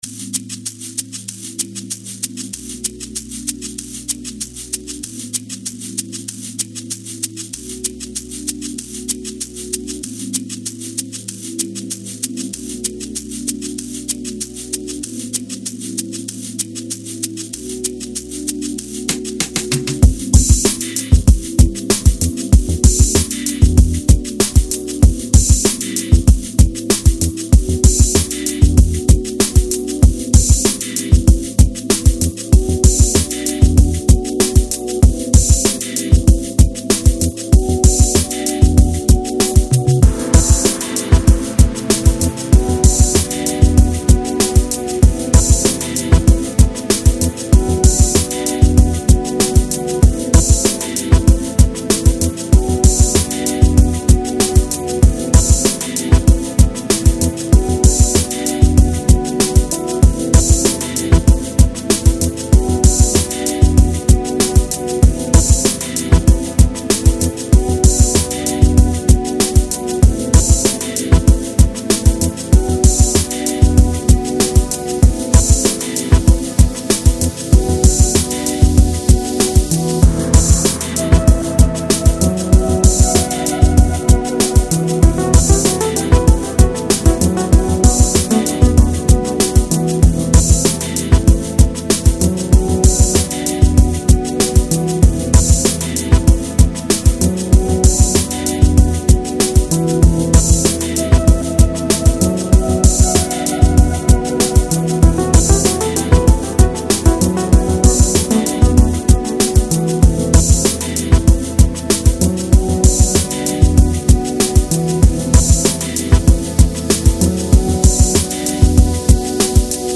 Lounge, Chill Out, Downtempo